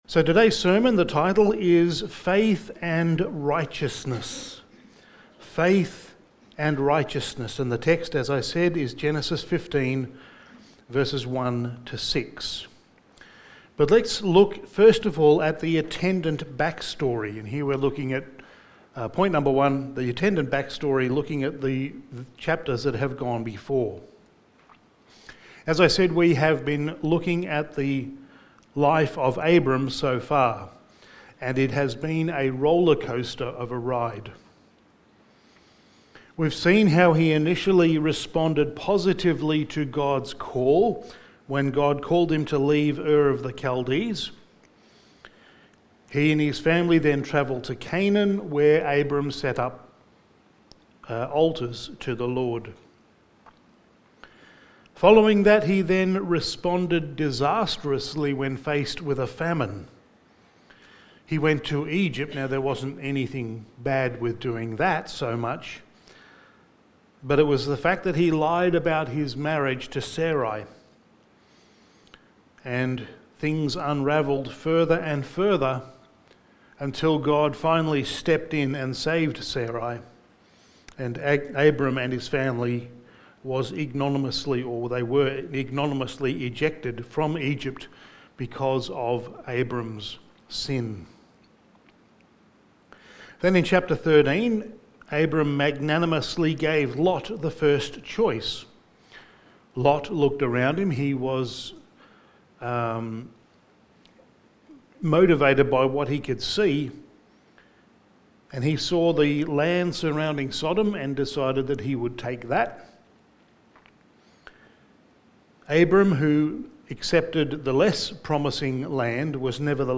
Sermon
Genesis Series Passage: Genesis 15:1-6 Service Type: Sunday Morning Sermon 23 « Abram’s Magnificient Faith